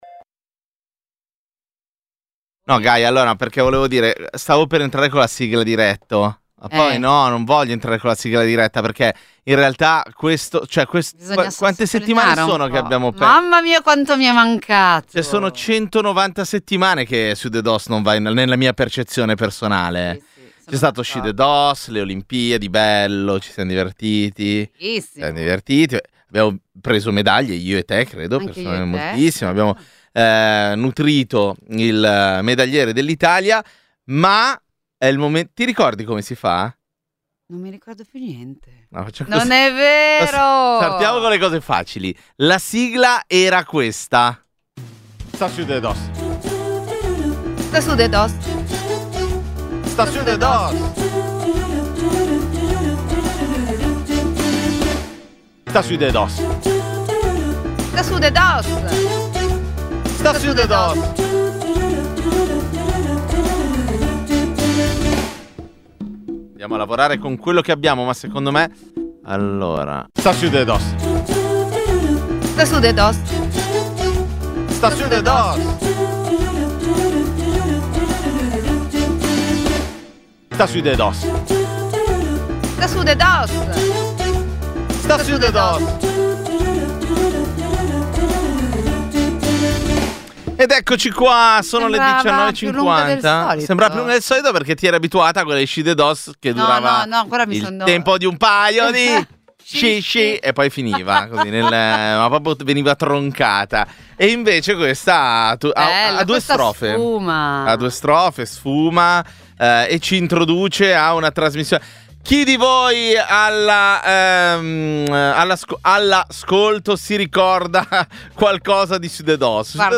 Sudedoss è il programma di infotainment che ogni domenica sera dalle 19.45 alle 21.30 accompagna le ascoltatrici e gli ascoltatori di Radio Popolare con leggerezza, ironia e uno sguardo semiserio sull’attualità.